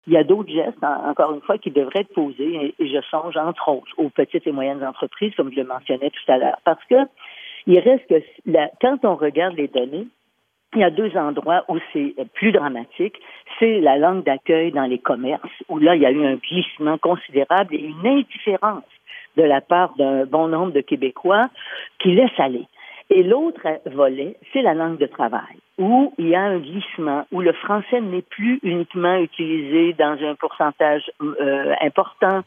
Pauline Marois fue entrevistada sobre ese tema este miércoles 4 de septiembre en el programa matutino Tout un matin (Toda una mañana) de la radio francesa de Radio Canadá y explica su preocupación.